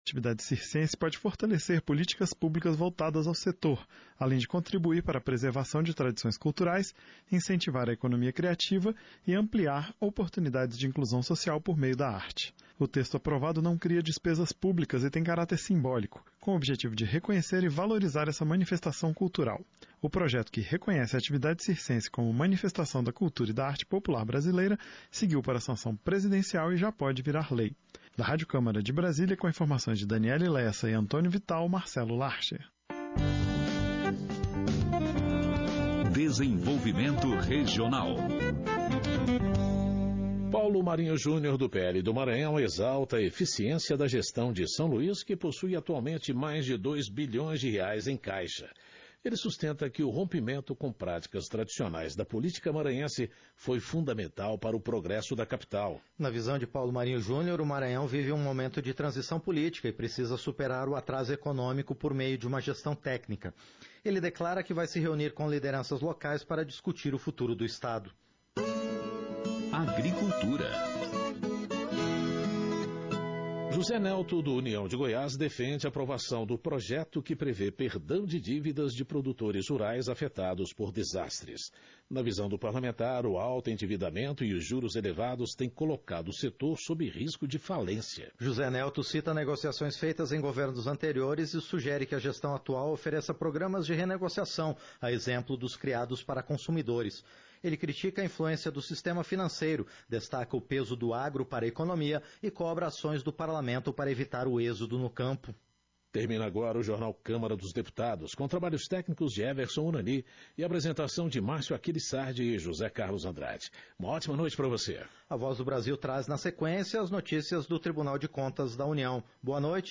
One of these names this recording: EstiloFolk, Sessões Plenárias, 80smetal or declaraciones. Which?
Sessões Plenárias